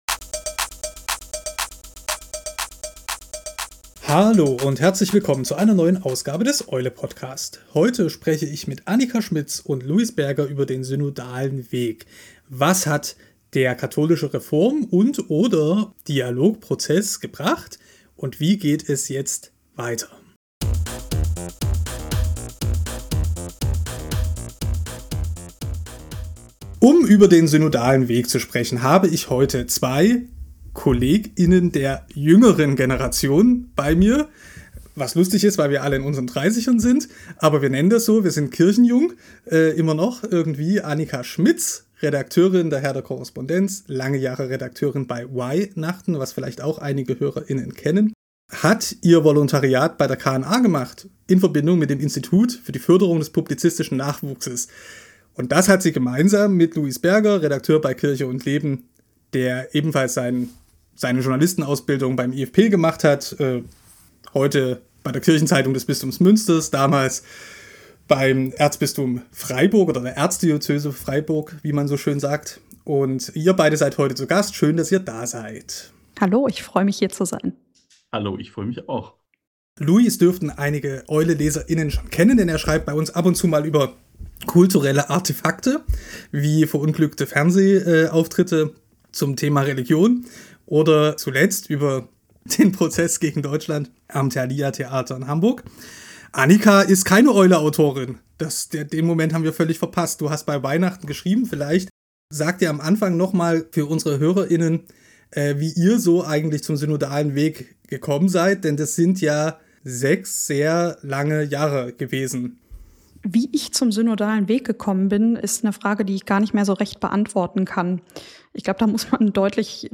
Was hat der katholische Dialog- und Reformprozess gebracht? Wie wird es mit den Inhalten des Synodalen Weges weitergehen? Das JournalistInnen-Konzil zur Zukunft der katholischen Kirche: